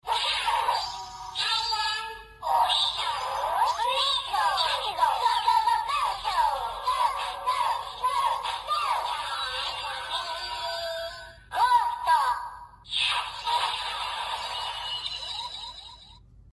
鬼仔饱藏音效.MP3